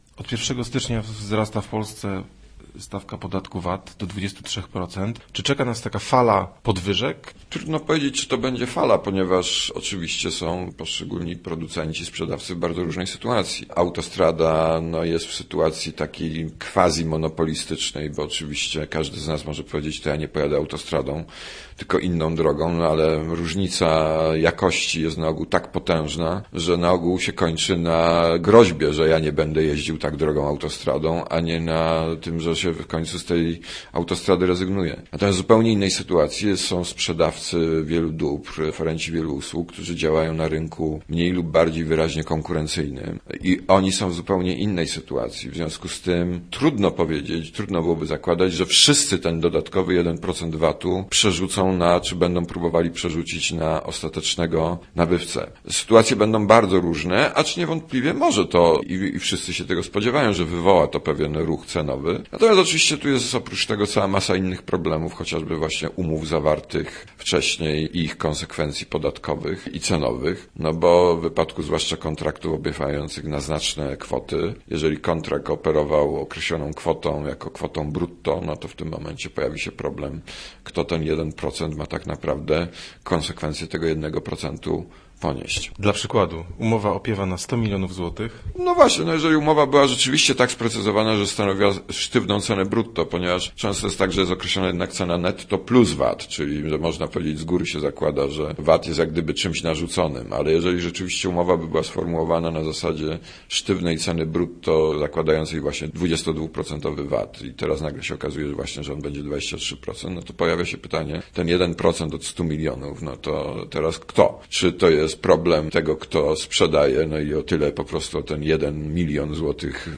i0epx0jshb9r6o3_vat_rozmowa.mp3